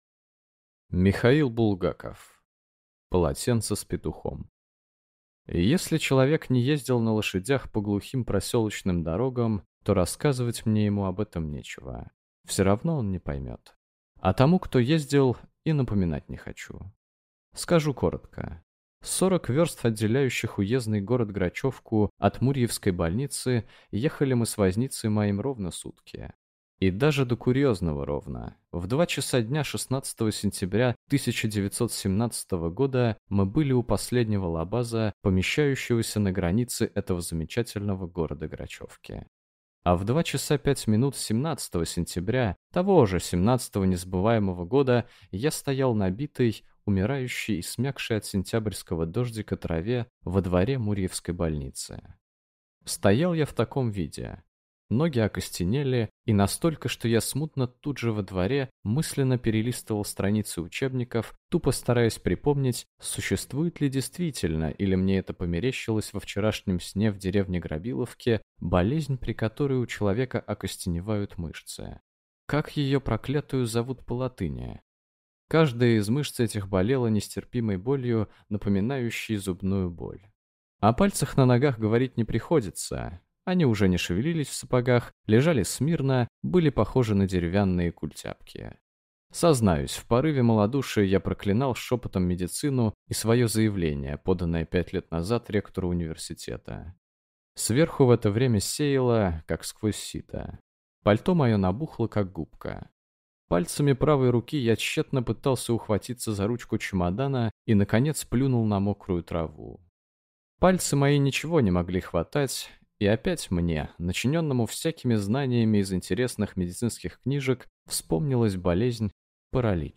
Аудиокнига Полотенце с петухом | Библиотека аудиокниг